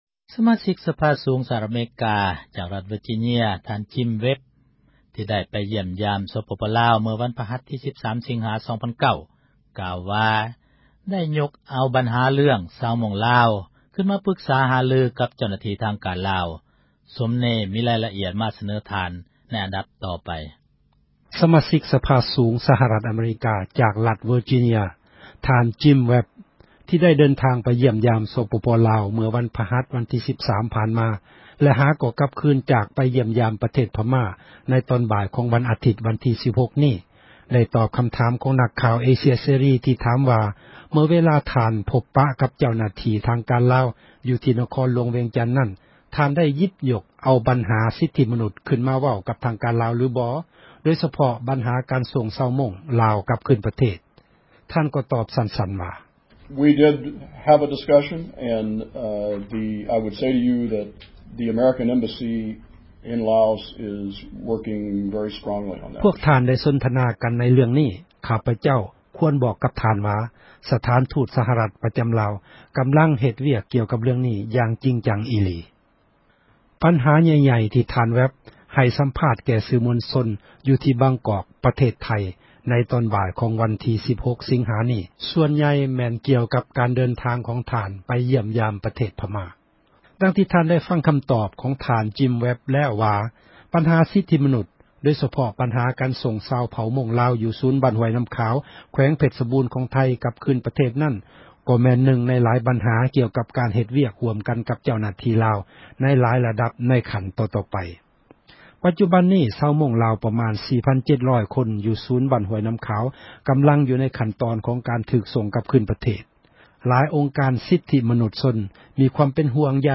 ທ່ານ Jim Webb ໄດ້ຕອບຄໍາຖາມ ຂອງນັກຂ່າວ ເອເຊັຽເສຣີ ທີ່ຖາມວ່າເມື່ອເວລາ ທ່ານພົບປະກັບ ເຈົ້າໜ້າທີ່ທາງການລາວ ຢູ່ທີ່ ນະຄອນຫລວງ ວຽງຈັນນັ້ນ ທ່ານໄດ້ຍົກເອົາປັນຫາ ສິດທິມະນຸດ ຂື້ນມາເວົ້າກັບ ທາງການລາວຫລືບໍ່ ໂດຍສະເພາະປັນຫາ ການສົ່ງຊາວມົ້ງລາວ ກັບຄືນປະເທດ ທ່ານກໍຕອບສັ້ນໆວ່າ: